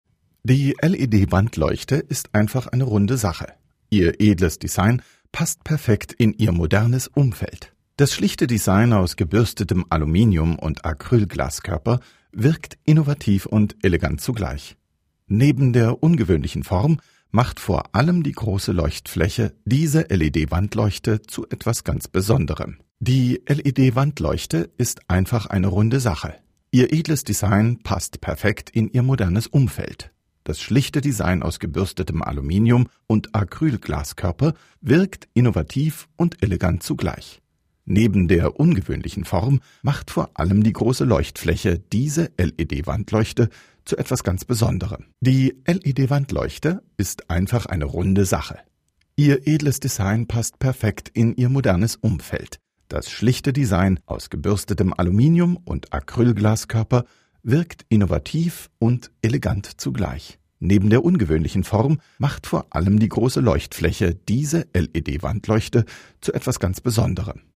Deutscher Sprecher, Werbesprecher für Einkaufsparks und Center, Telfonjingles, Profischauspieler, Hörbücher, Hörspiele, Produzent, all inklusive, Overvoice, Voiceover, Dokfilm, Reportagen, Heimatfilm, Kommentar, Kurzfilm, Offsprecher, Synchron, Spielfilm, Dokumentation,
Sprechprobe: Industrie (Muttersprache):